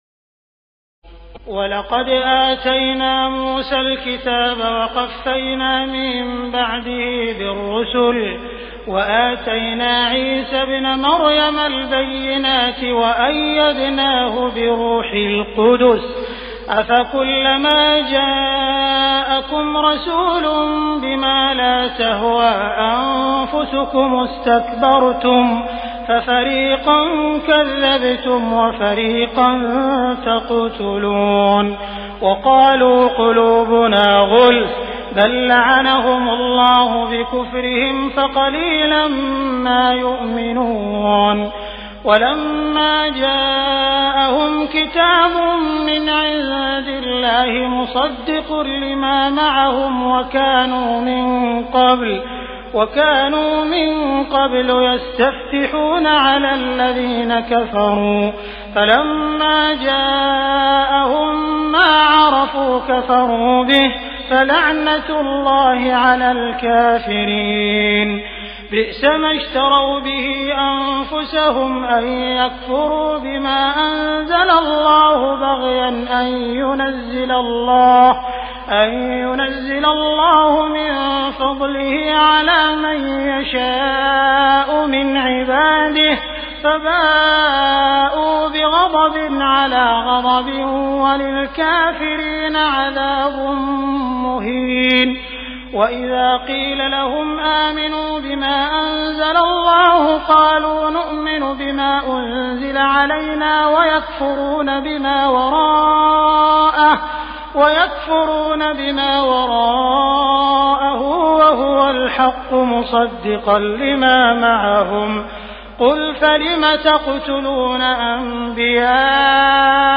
تراويح الليلة الثانية رمضان 1418هـ من سورة البقرة (87-157) Taraweeh 2st night Ramadan 1418H from Surah Al-Baqara > تراويح الحرم المكي عام 1418 🕋 > التراويح - تلاوات الحرمين